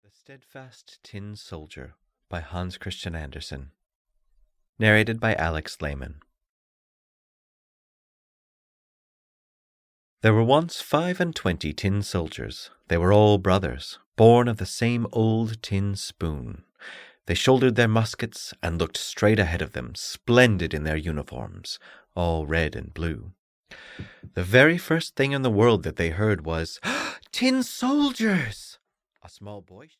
The Steadfast Tin Soldier (EN) audiokniha
Ukázka z knihy